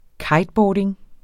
Udtale [ ˈkɑjdˌbɒːdeŋ ]